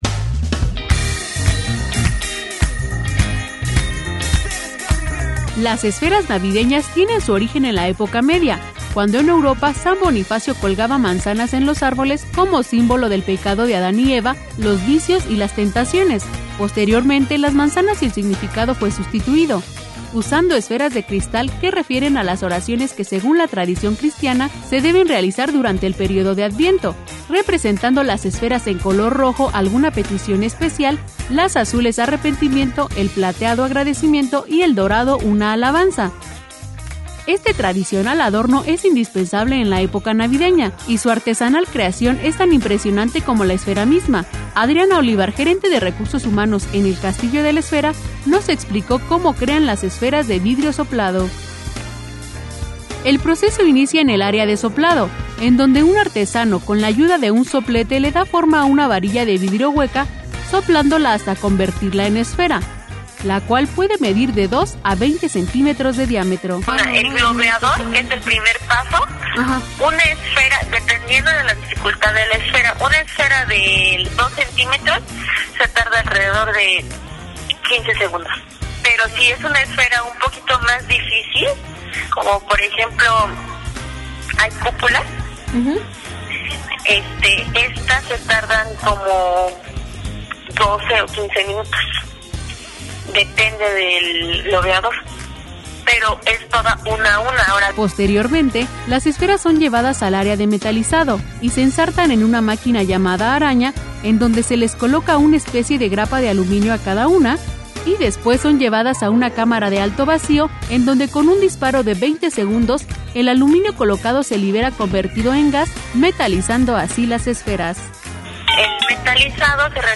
REPORTAJE.mp3